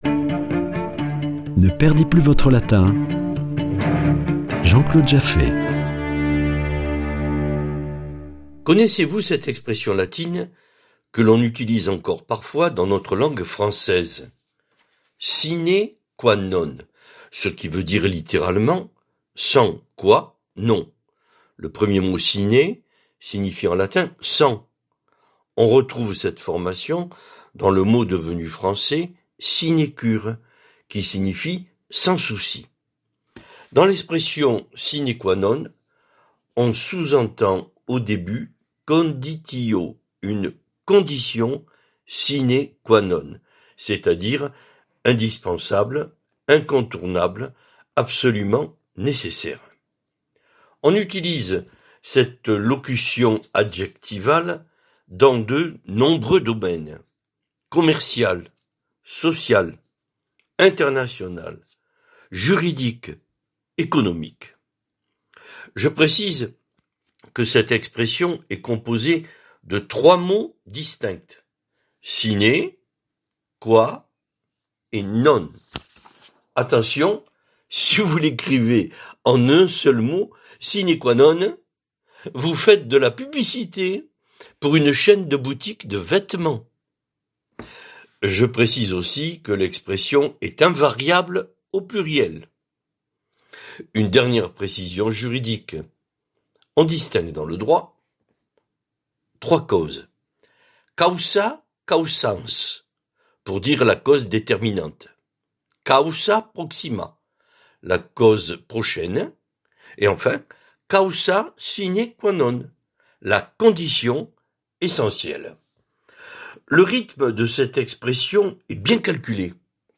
Chronique Latin